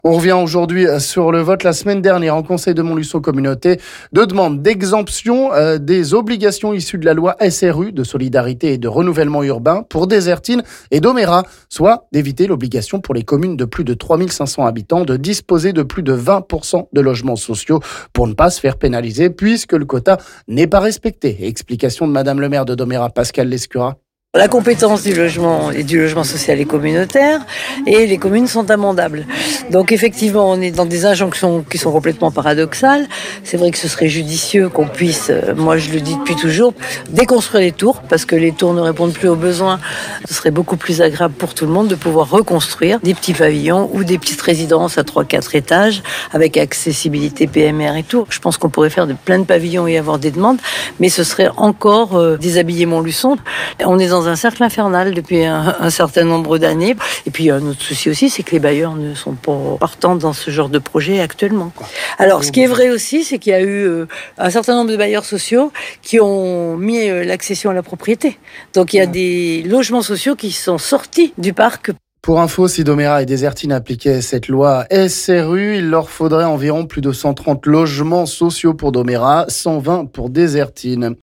Explications de Mme le maire de Domérat Pascale Lescurat...